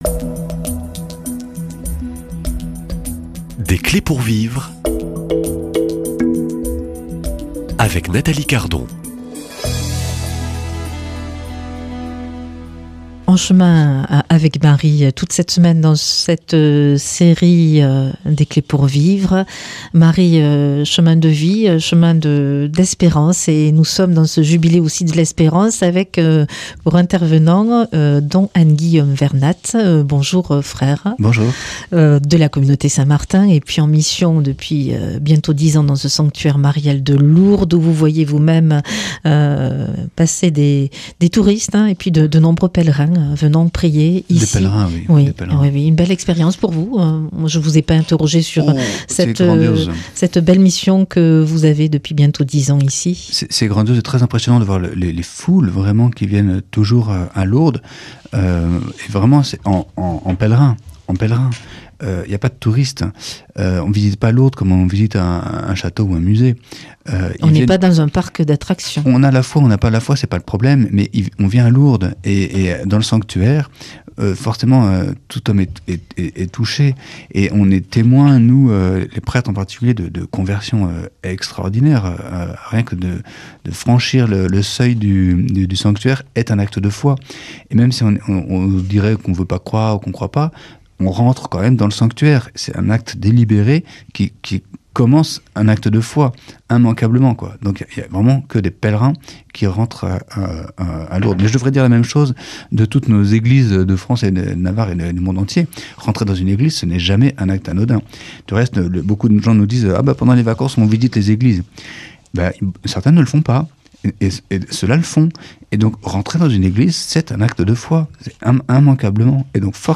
Dans ce quatrième entretien, nous approfondissons une autre dimension du mystère marial : Marie est aussi notre mère.